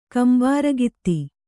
♪ kambāragitti